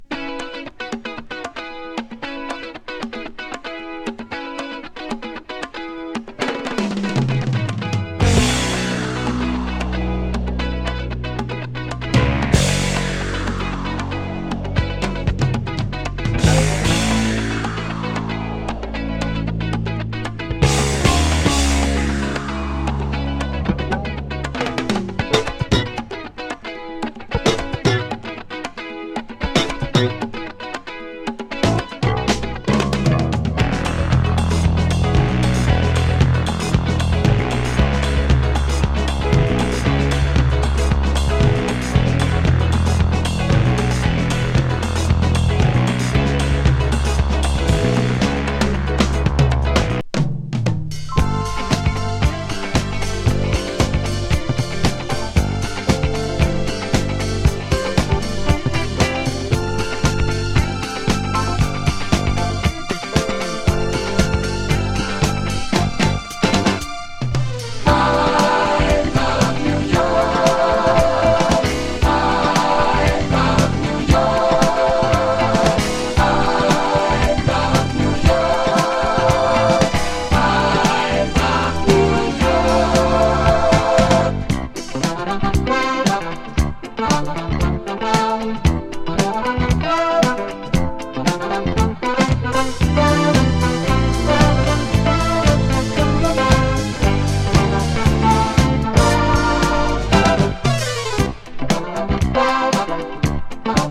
爽快シンセ・ディスコ・フュージョン
ボコーダー・グルーヴ